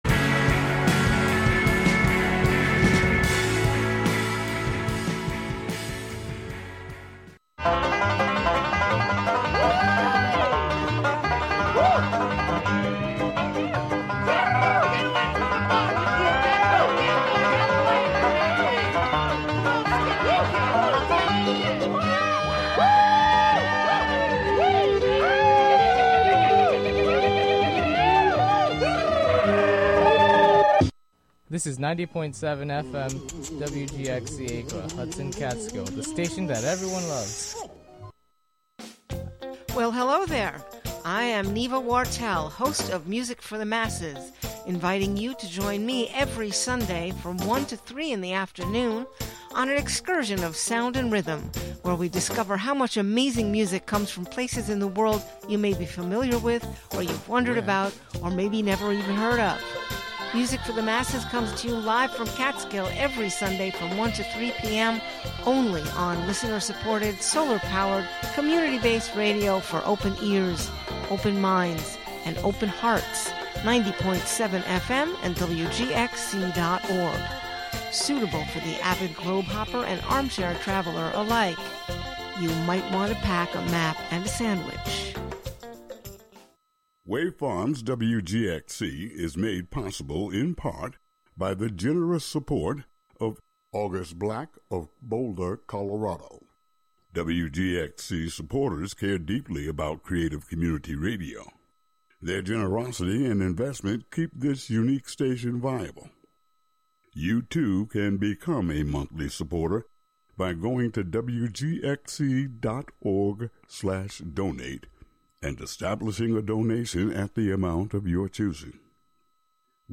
Tune in on Friday nights from 8 p.m. to midnight for music and sounds from artists and musicians living in the Hudson Valley, Capital District, and Western Massachusetts.